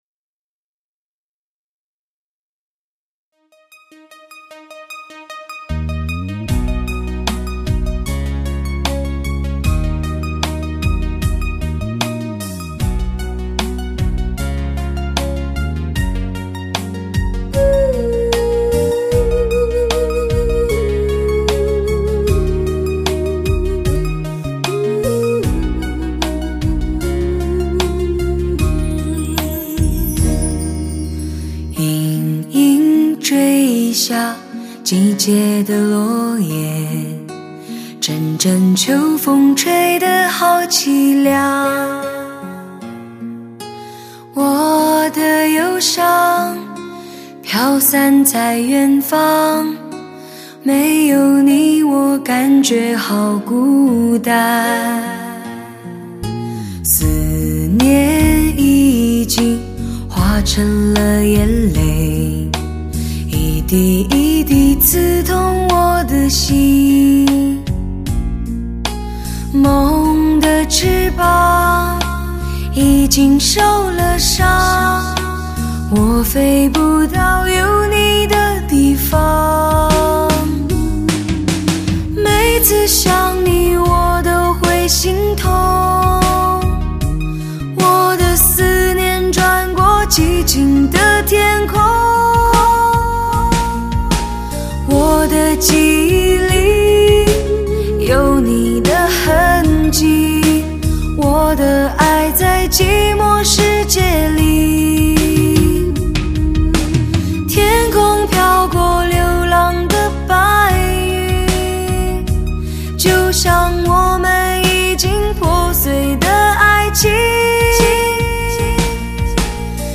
磁性的丝质嗓音，诠释出浪漫感性的忧伤！